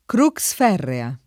kr2kS f$rrea]